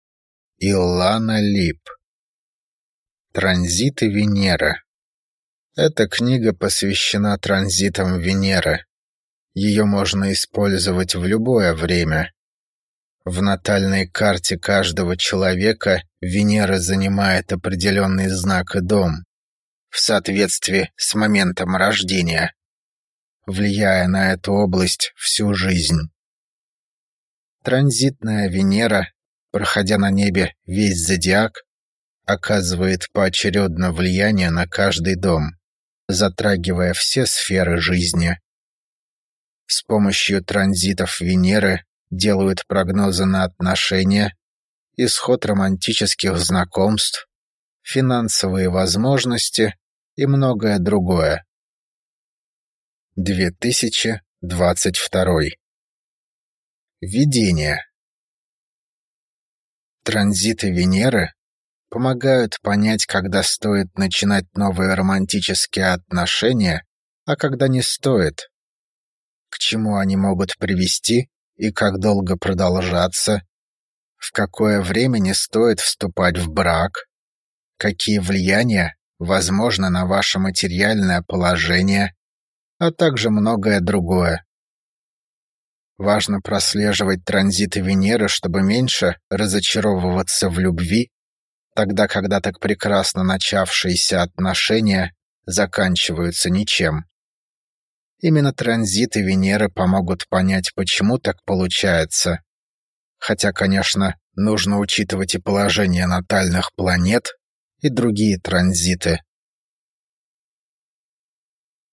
Аудиокнига Транзиты Венеры | Библиотека аудиокниг